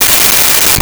Cell Phone Ring 08
Cell Phone Ring 08.wav